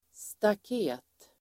Ladda ner uttalet
Uttal: [stak'e:t]